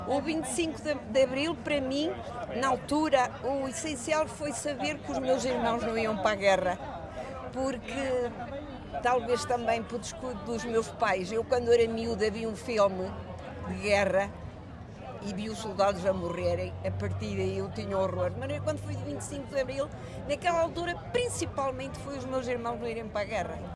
Terminada a Assembleia Municipal extraordinária, que assinalou o 25 de Abril, encontrámos, numa esplanada ali perto, um grupo de amigos que nos contou como foi vivido aquele dia.